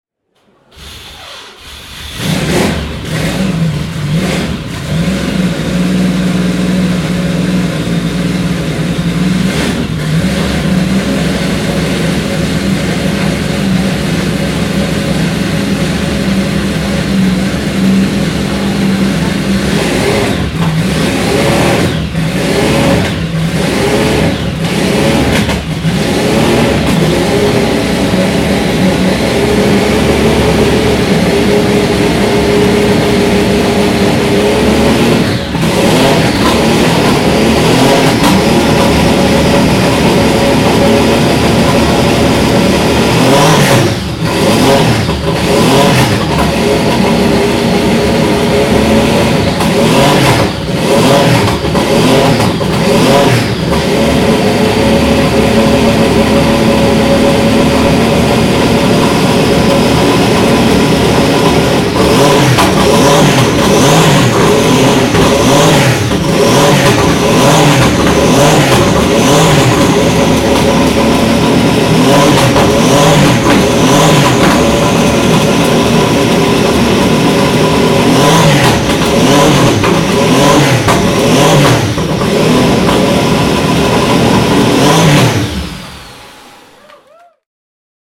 Porsche 911 R (1967) - Starten und Hochdrehen an der Porsche Sound-Nacht 2013